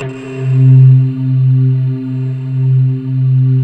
Index of /90_sSampleCDs/USB Soundscan vol.28 - Choir Acoustic & Synth [AKAI] 1CD/Partition D/03-PANKALE